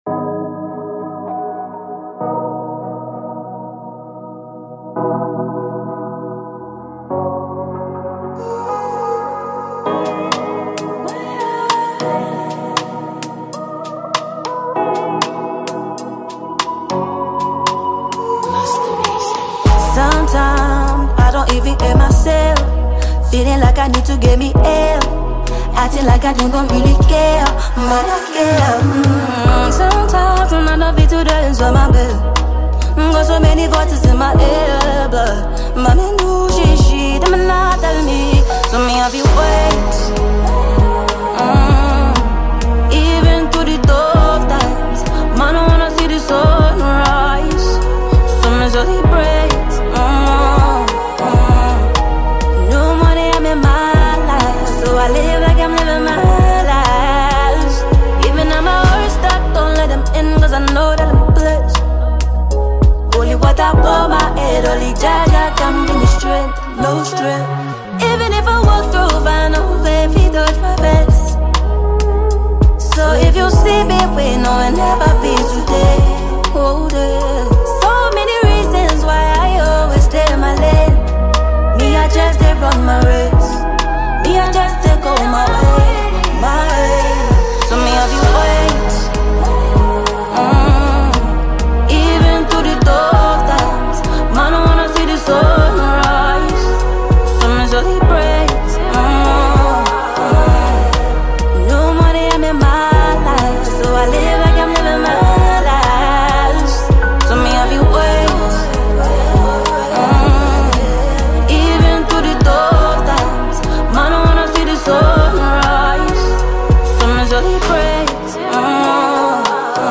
Ghanaian female singer and songwriter